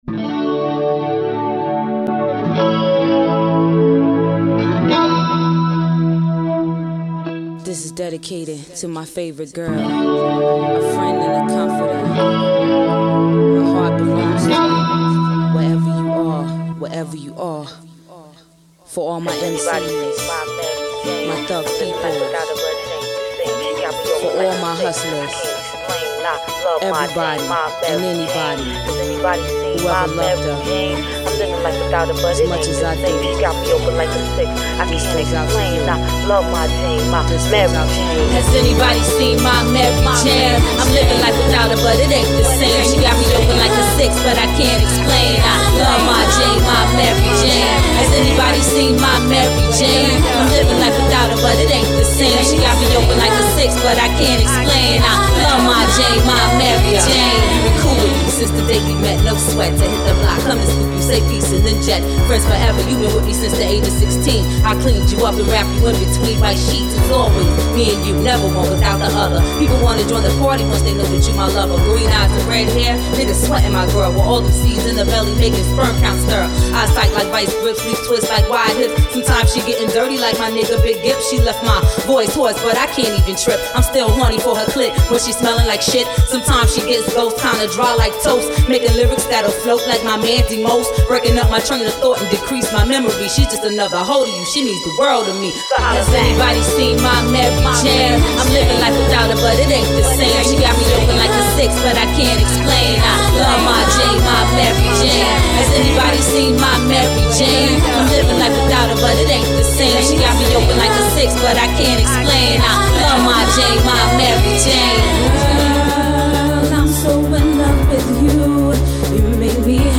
We actually recorded it a while ago, but recently re-mixed it down. I typically put the vocals on a mono track (at least for the verses of a hip hop song), but on this one I decided to make the vocals wide stereo and put most of the music in the "middle". It's a bit different sounding, but I like it.